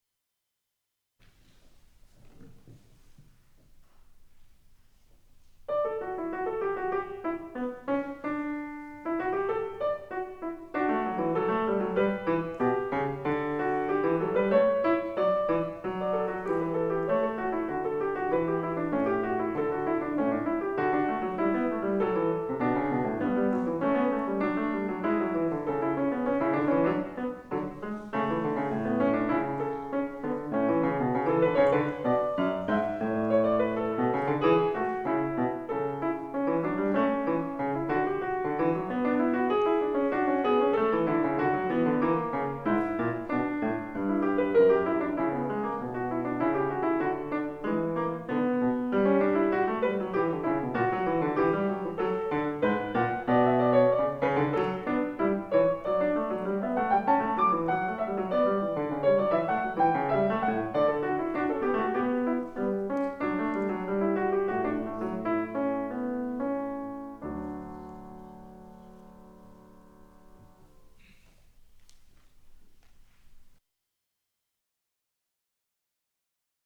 自作自演
2002.3.3 イズミティ２１ 小ホール
自分の作品なのにミスタッチ多いです・・・。